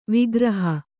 ♪ vigraha